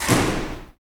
SI2 DOORS04L.wav